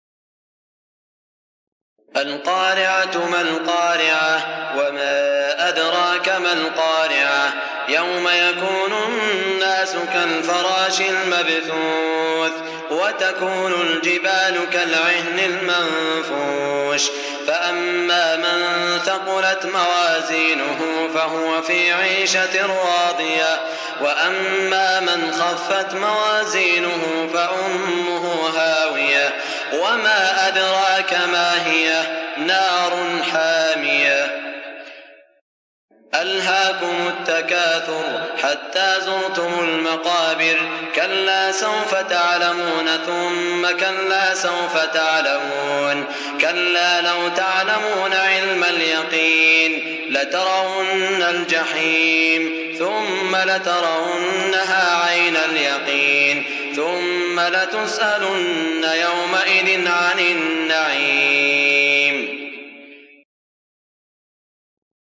سورتي القارعة و التكاثر من عام ١٤٠٨ | بمدينة الرياض > الشيخ سعود الشريم تلاوات ليست من الحرم > تلاوات وجهود أئمة الحرم المكي خارج الحرم > المزيد - تلاوات الحرمين